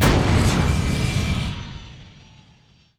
engine_start_006.wav